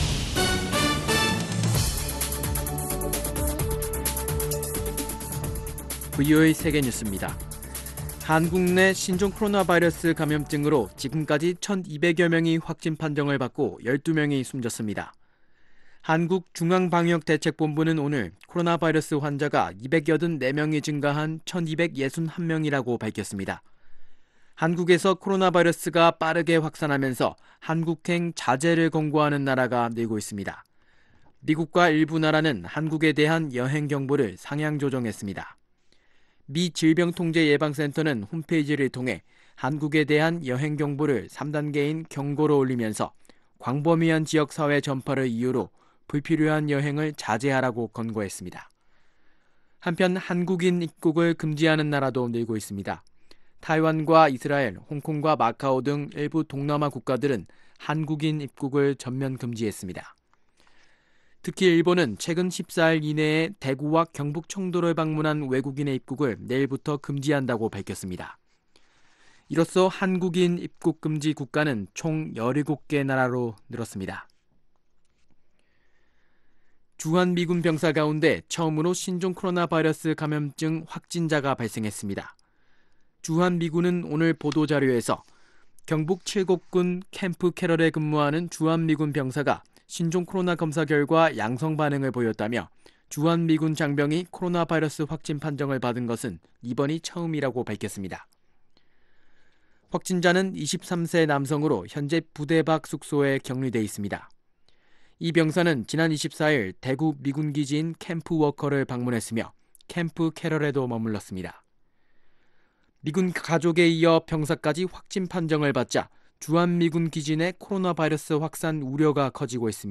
세계 뉴스와 함께 미국의 모든 것을 소개하는 '생방송 여기는 워싱턴입니다', 2019년 2월 26일 저녁 방송입니다. ‘지구촌 오늘’은 한국에 주둔 중인 미군 병사 1명이 최초로 신종 코로나바이러스 확진 판정을 받았다는 소식, ‘아메리카 나우’에서는 25일에 열린 민주당 대선주자 10차 토론에서, 버니 샌더스 상원의원에게 공격이 집중됐다는 이야기를 소개합니다. ‘지성의 산실, 미국 대학을 찾아서’에서는 '미 육군사관학교(United States Military Academy)'를 소개합니다.